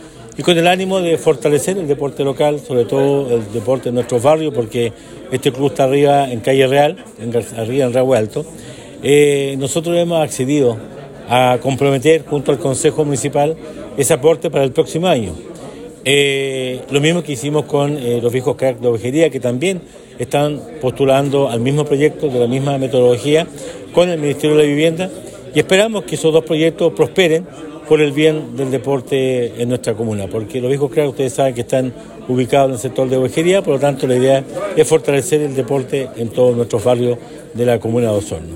El jefe comunal, señaló que esta obra responde a una necesidad planteada por los propios vecinos de Rahue Alto, quienes han expresado su interés por contar con un espacio adecuado para el desarrollo de actividades deportivas y comunitarias.